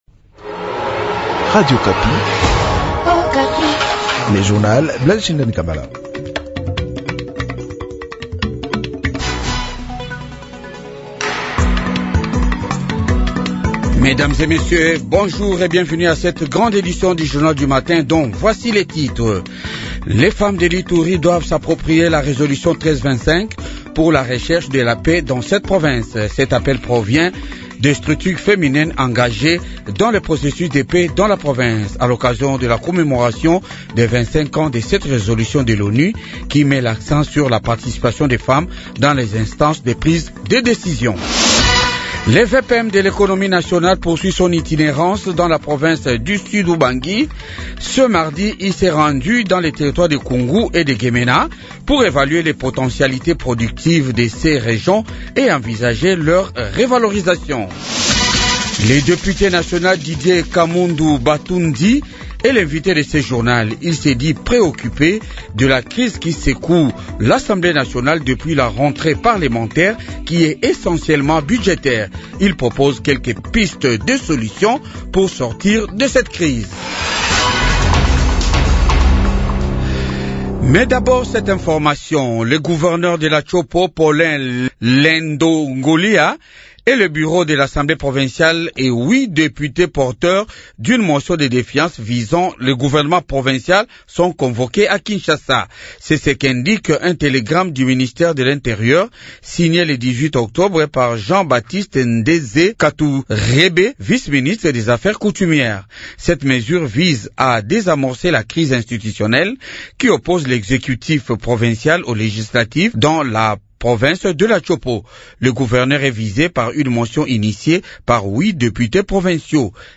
JOURNAL DE 8H